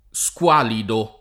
squalido [ S k U# lido ]